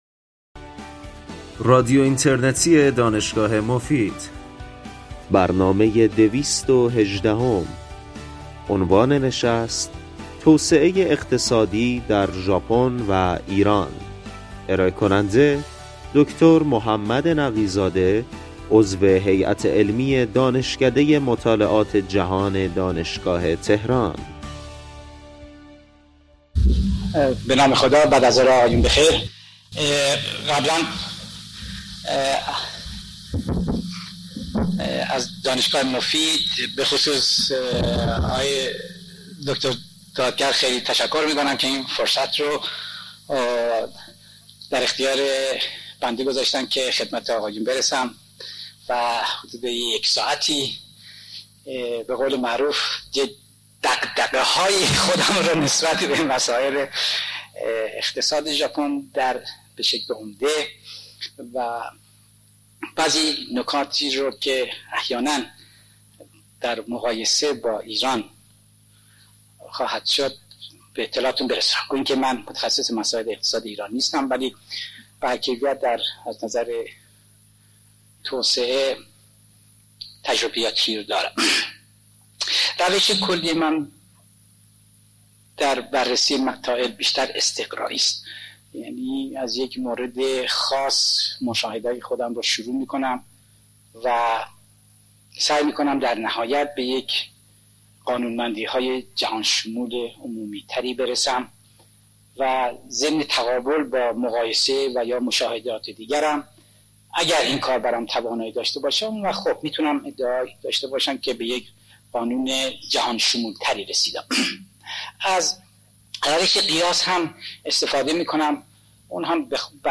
بخش پایانی برنامه به پرسش و پاسخ اختصاص دارد.